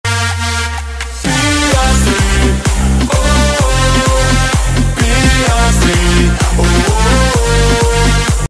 piastri oh ooooooh Meme Sound Effect
piastri oh ooooooh.mp3